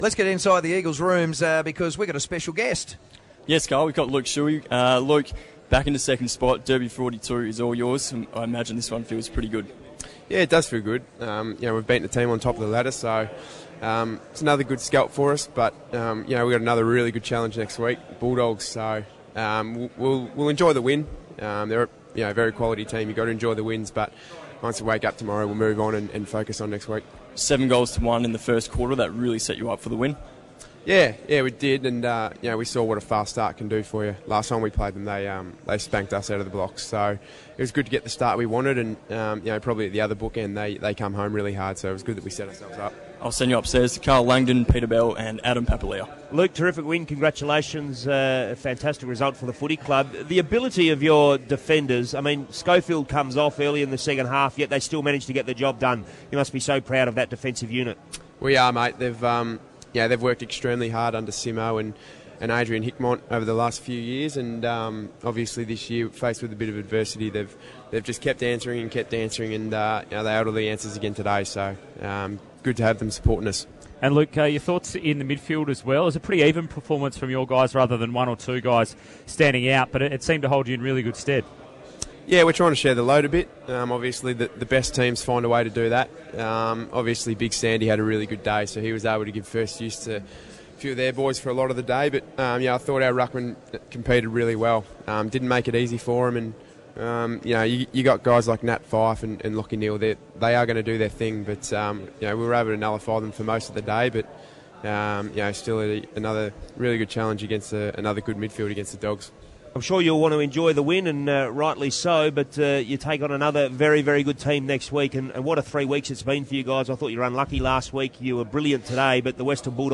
Luke Shuey Post Game Interview
Luke Shuey of the West Coast Eagles speaks to the 6PR commentary team after the game.